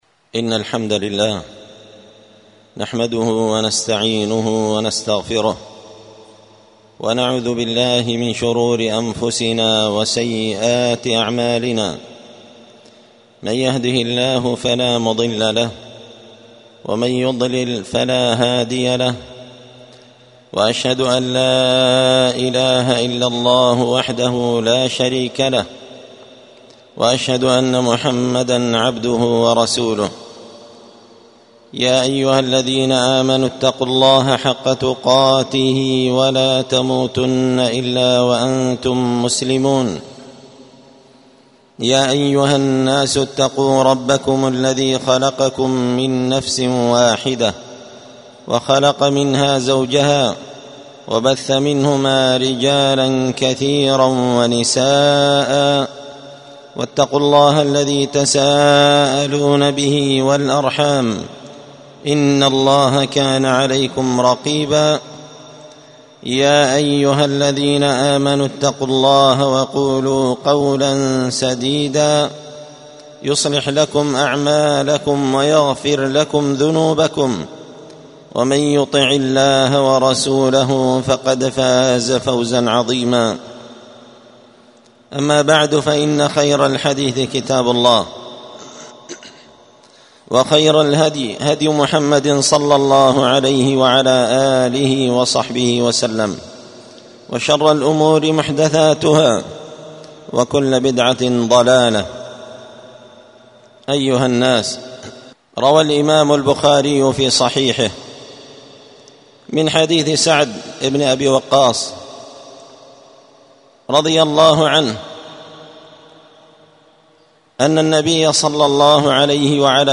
الجمعة 15 شعبان 1446 هــــ | الخطب والمحاضرات والكلمات | شارك بتعليقك | 44 المشاهدات